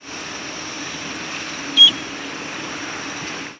plaintive: A soft, mournful sound, usually off-key (e.g.,
Rose-breasted Grosbeak).